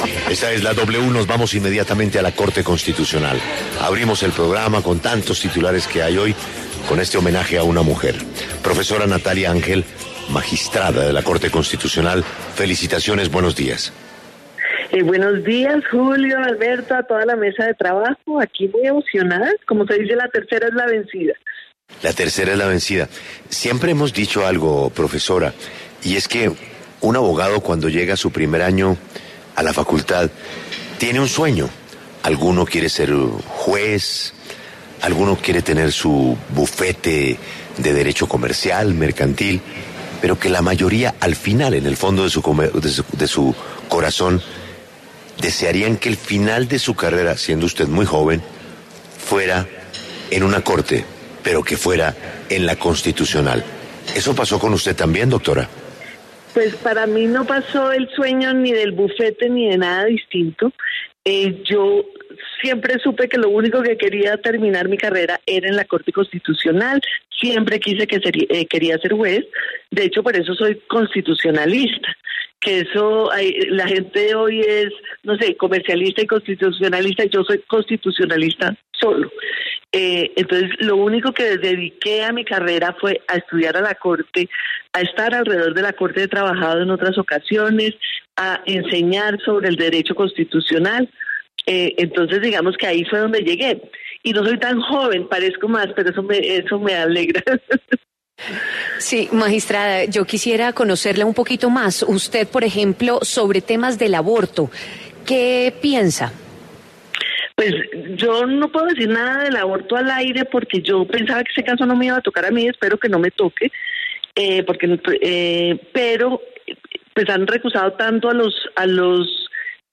En diálogo con La W, la magistrada Natalia Ángel habló sobre el nuevo cargo que ocupa tras la salida del magistrado Alberto Ríos.